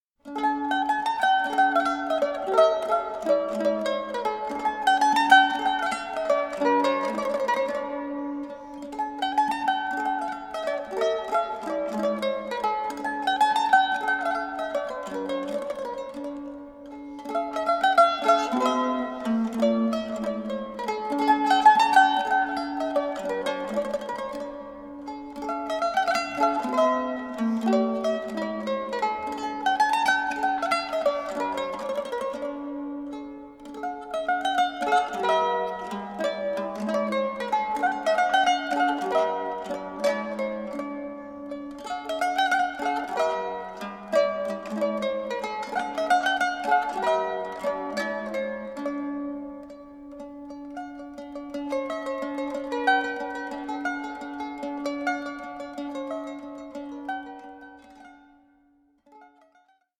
neapolitan mandolin